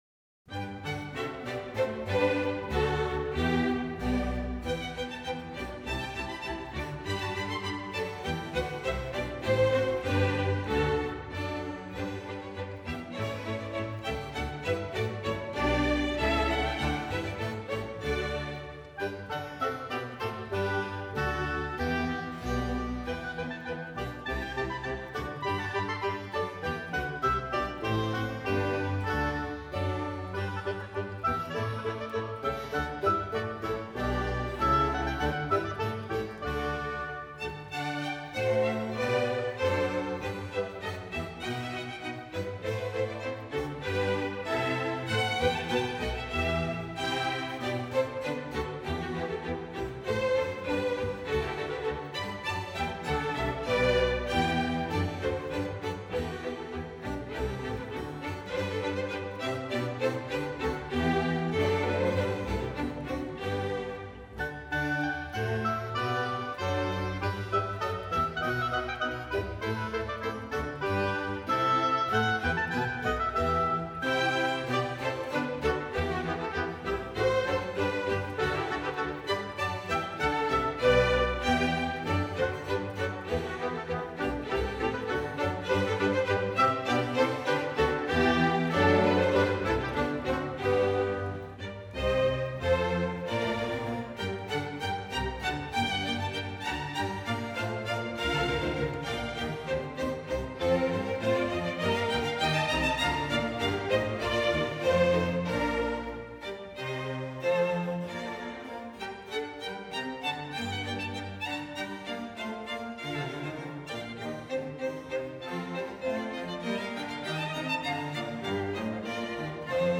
两首利戈东舞曲1和2
利戈东舞曲是法国古代普罗旺斯的一种民间舞曲，采用单二或四拍子，类似布列舞曲，是最轻巧的巴洛克舞曲。